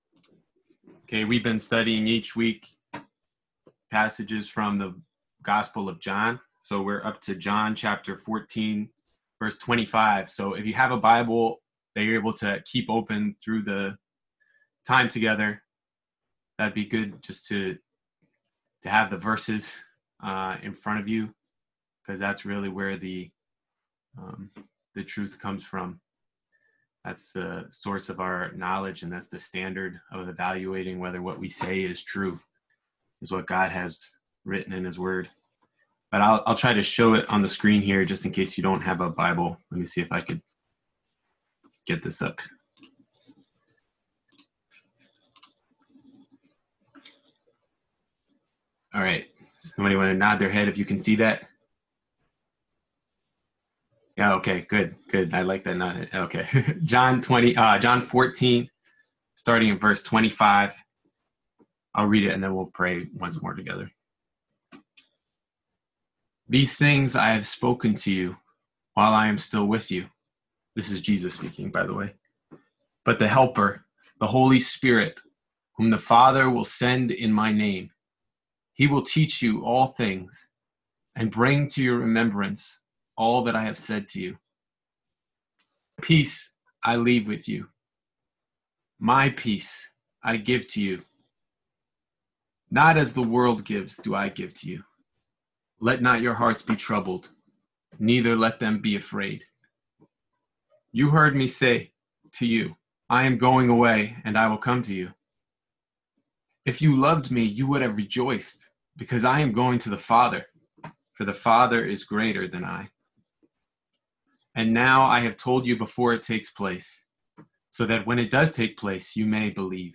This talk was given over Zoom during the Coronavirus pandemic and NYS shutdown.